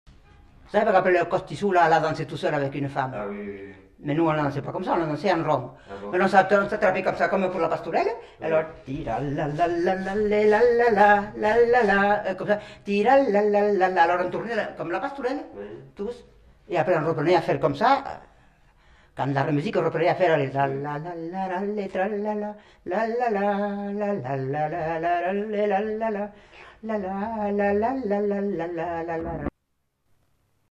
Aire culturelle : Haut-Agenais
Lieu : Castillonnès
Genre : chant
Effectif : 1
Type de voix : voix de femme
Production du son : fredonné
Danse : youska
Notes consultables : L'interprète explique, chante et danse en même temps.